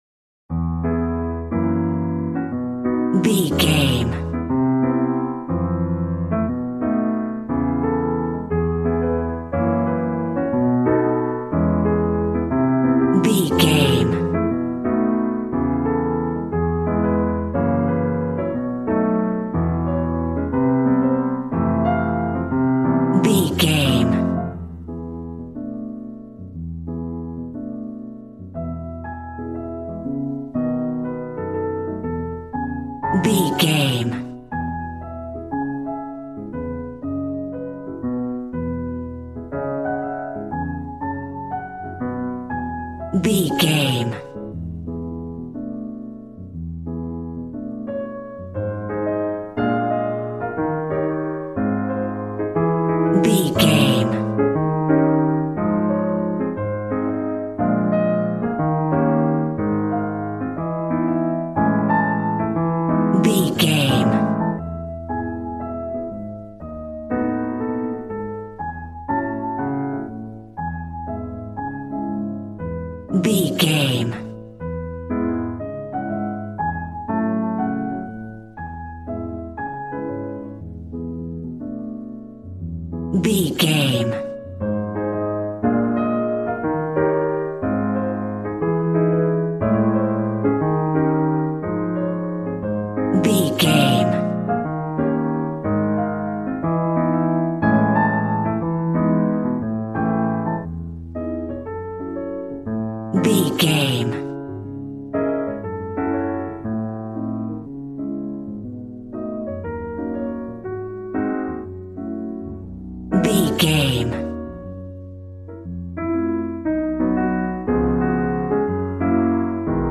Smooth jazz piano mixed with jazz bass and cool jazz drums.,
Ionian/Major
cool